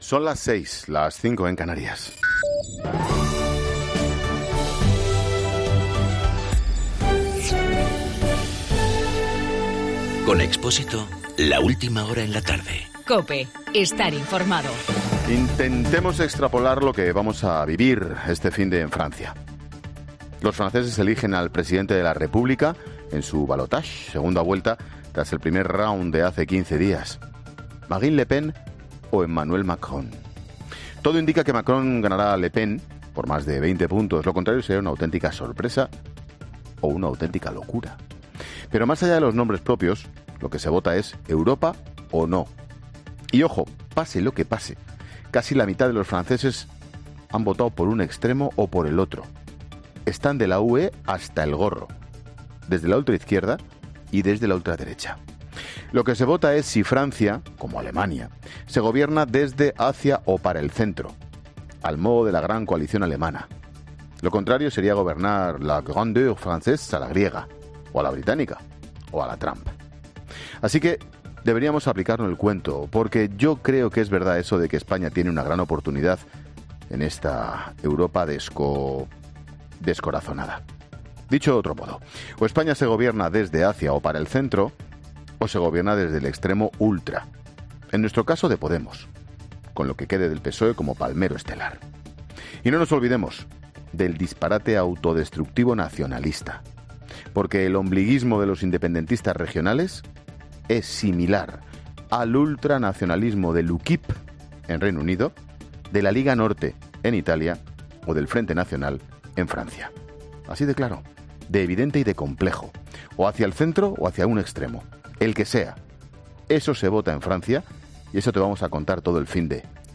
AUDIO: Monólogo 18h.